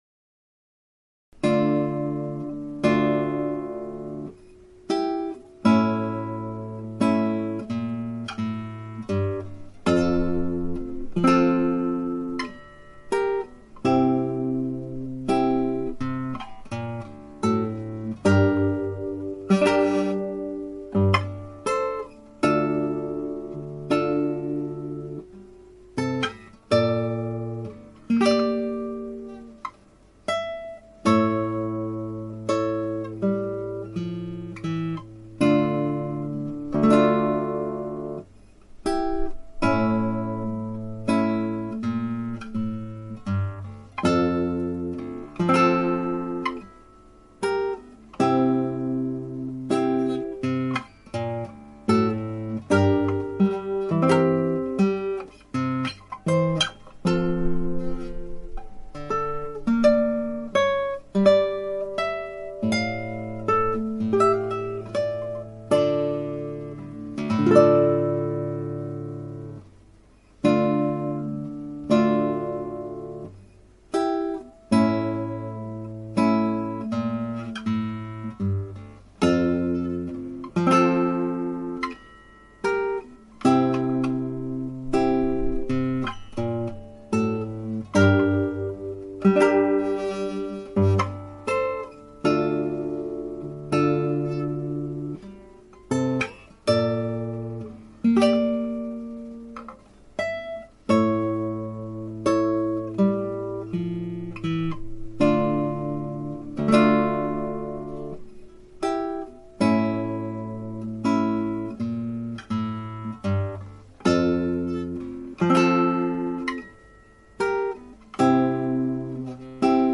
(アマチュアのクラシックギター演奏です [Guitar amatuer play] )
テーマと変奏のテンポ感がしっくりしない。録音も第2変奏の終わり部分の所で石油ファンヒーターの自動停止音がピーピー。最後の繰り返しのテーマはテンポが最悪の出来。
ただ、私はハープシコードの原曲楽譜を参考に一部を変更して弾きました。